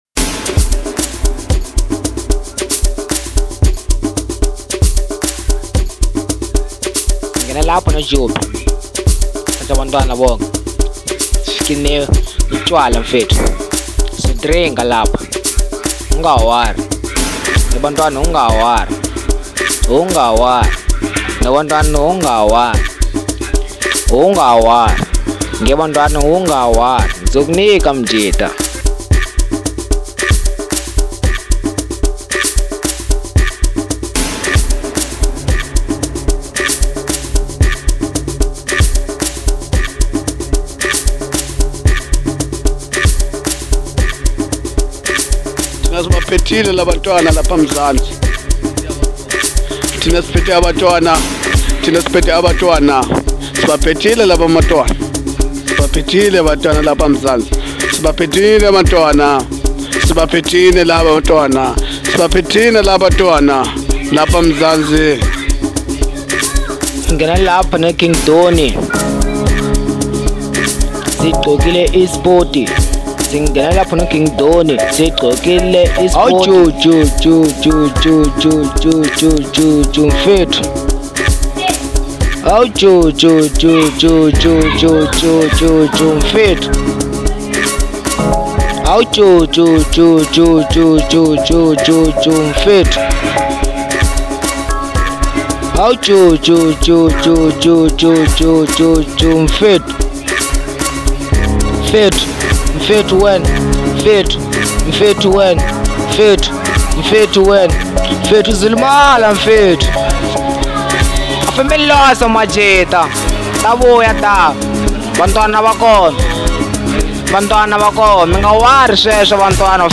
06:22 Genre : Amapiano Size